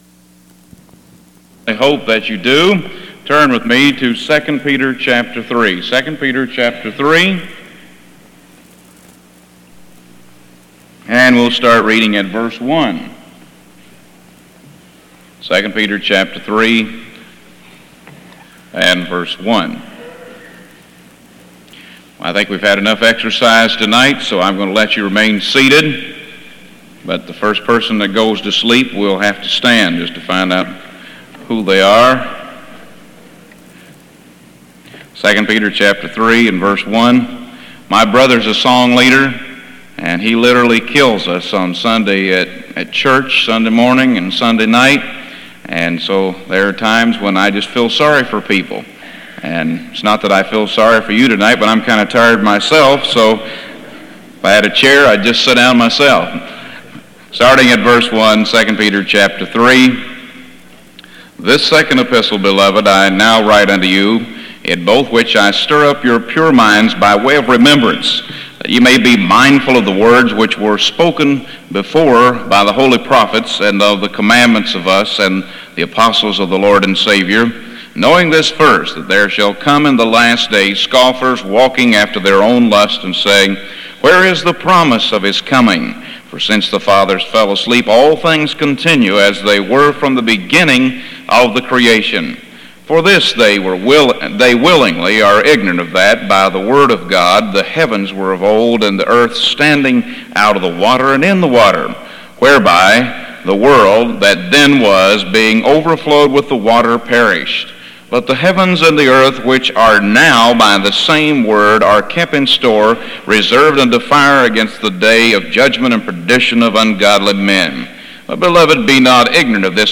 Houghton Bible Conference 1982